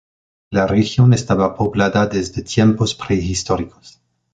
/poˈblada/